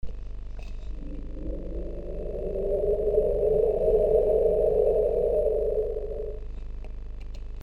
ambientWIND-2.mp3